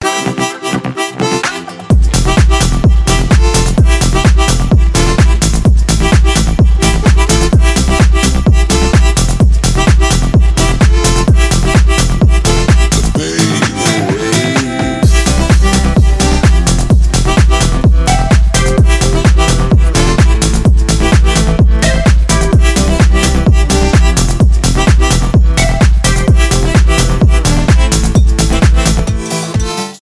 кантри , folktronica
танцевальные , инструментальные , губная гармошка